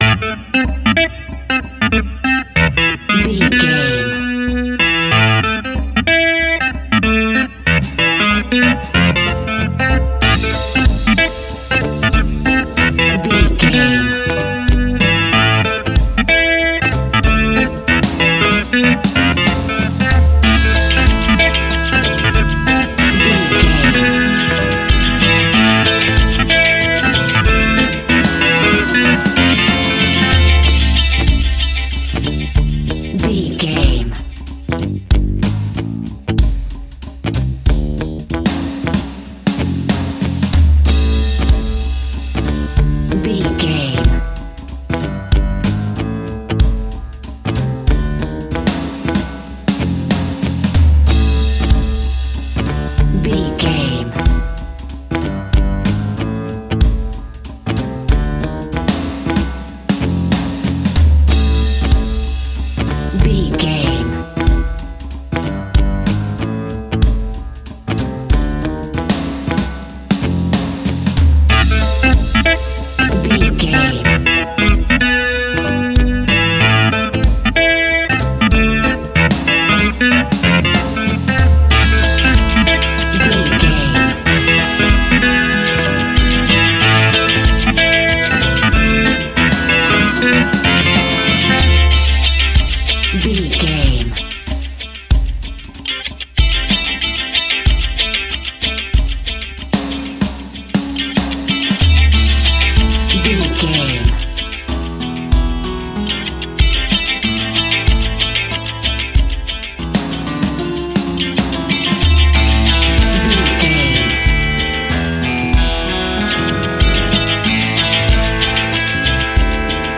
Aeolian/Minor
instrumentals
laid back
chilled
off beat
drums
skank guitar
hammond organ
transistor guitar
percussion
horns